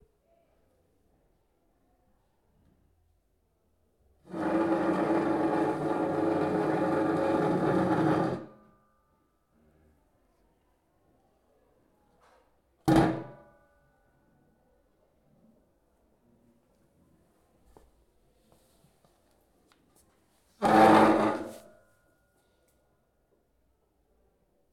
Arrastre de una silla
Ruido de una silla cuando la alguien la arrastra.